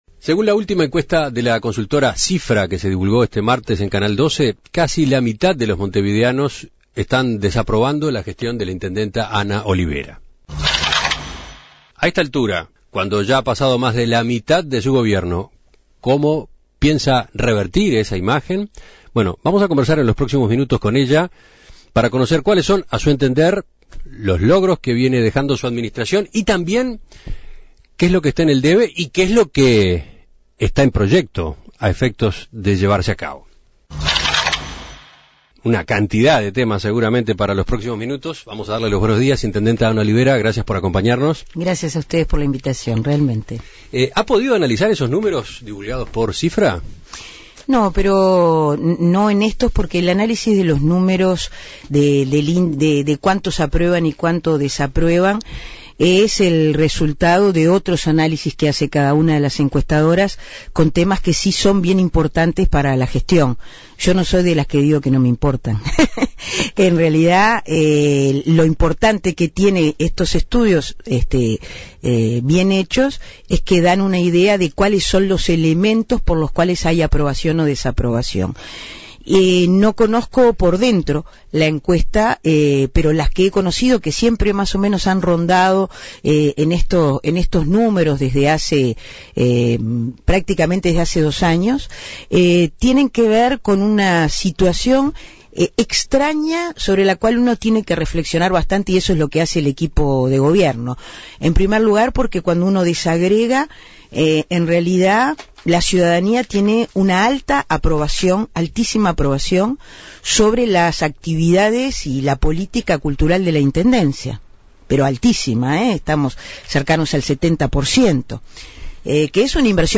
Según la última encuesta de Cifra, el 48% de los montevideanos desaprueban la gestión de la intendenta Ana Olivera. Entrevistada por En Perspectiva, la jefa comunal entendió que dicha desaprobación está planteada en tres áreas: limpieza, tránsito y transporte.
Entrevistas